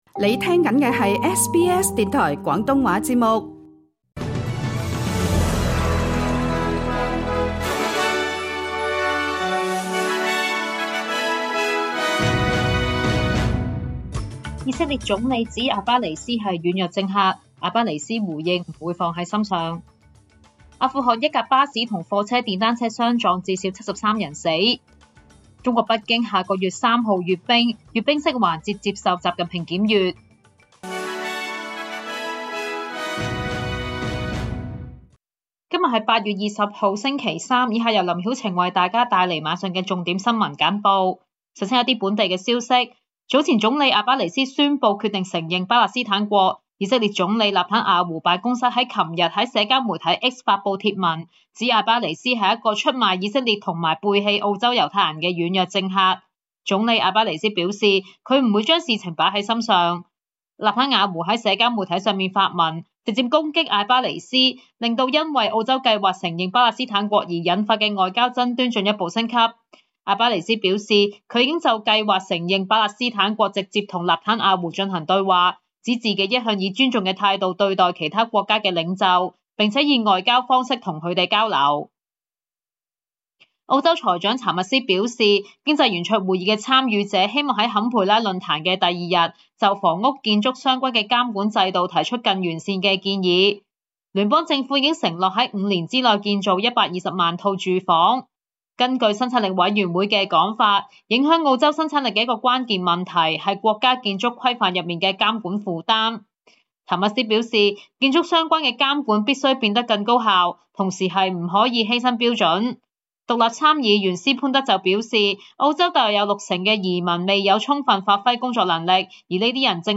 SBS晚間新聞（2025年8月20日）